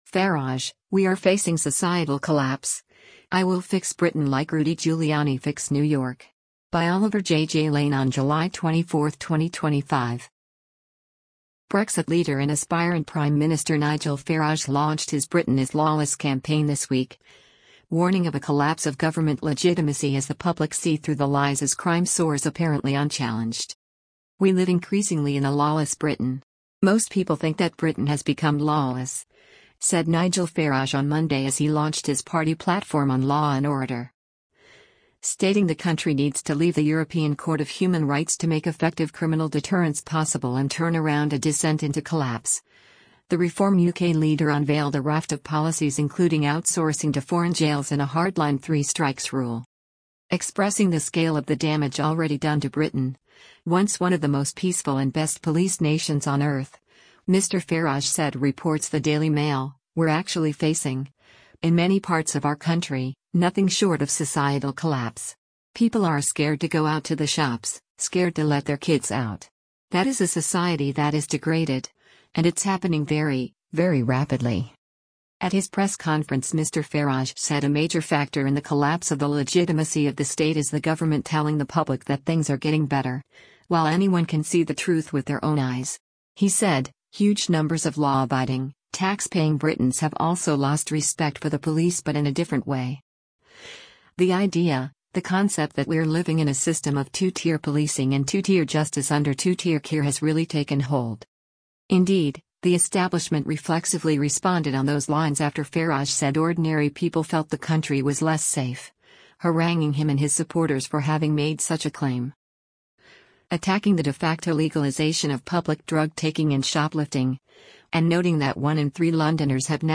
LONDON, UNITED KINGDOM - JULY 21: Reform UK leader Nigel Farage speaks during a press conf